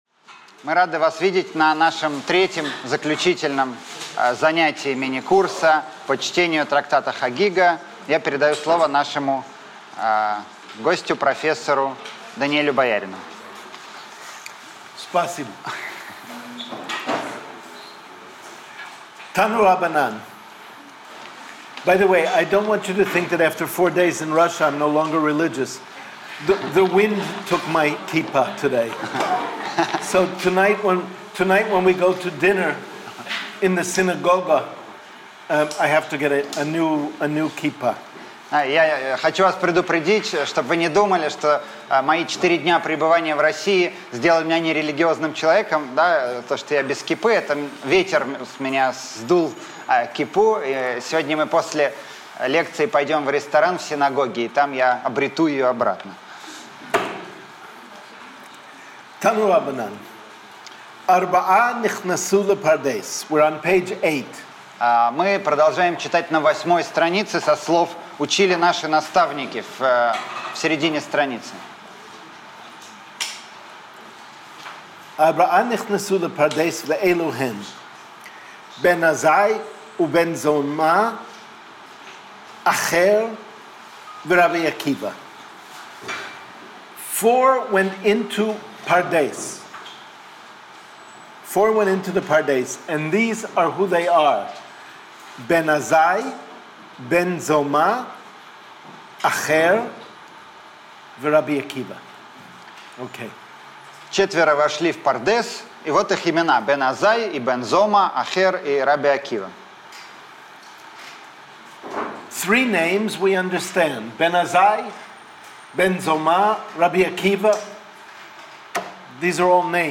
Аудиокнига Мистические главы Талмуда. Часть 3 | Библиотека аудиокниг